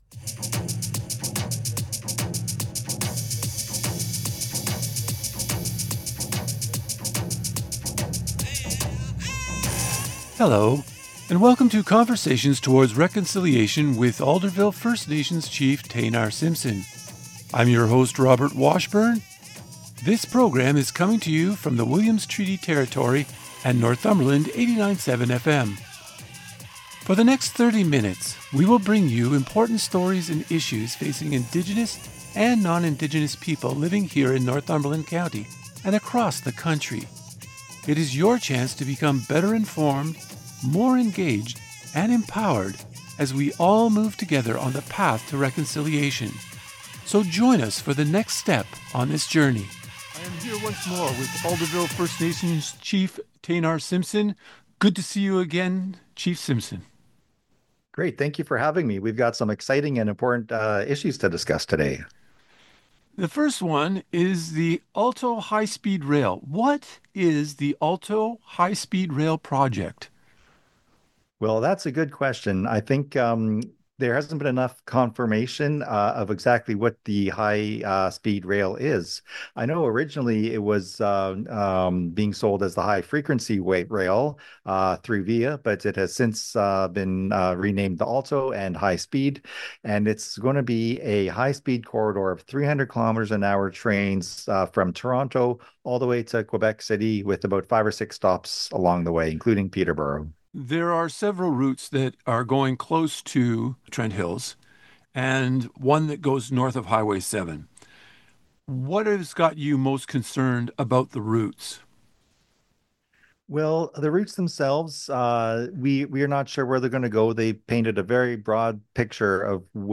In this interview, he also discussed the Northumberland County Roads Project, in which Alderville is collaborating with the county to improve County Road 45 through the community by adding sidewalks, turning lanes, and safer infrastructure. The conversation included updates about two centenarians and ended with discussions about upcoming community events and announcements.